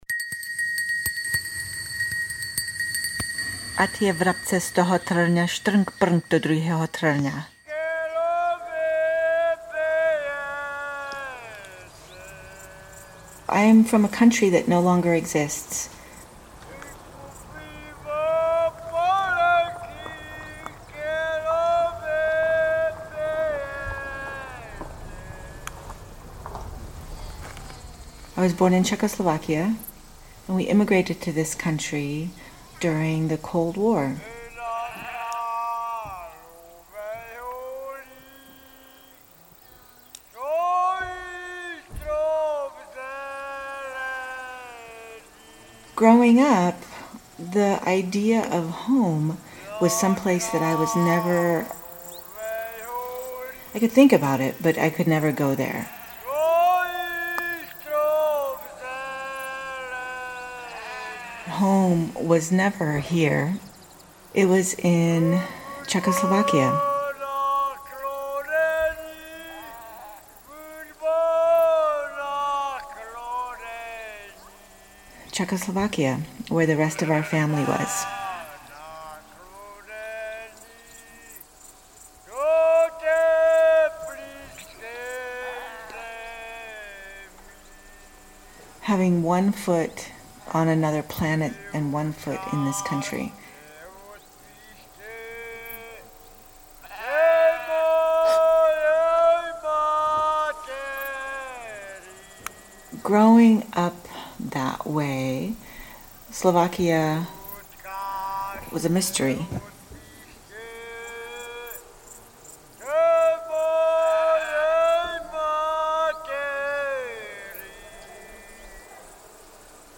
Played a second time less attenuated, the original recording sounds fuller but is shortened by several cuts.